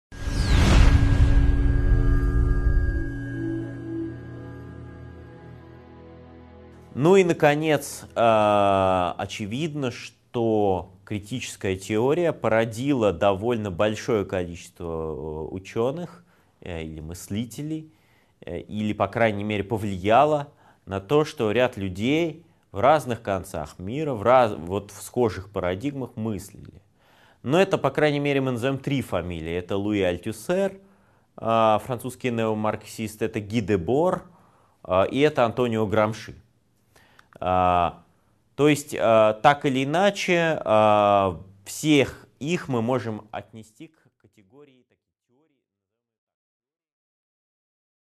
Аудиокнига 6.7 Критическая теория: Альтюссер, Дебор, Грамши | Библиотека аудиокниг